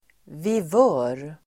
Ladda ner uttalet
Uttal: [viv'ö:r]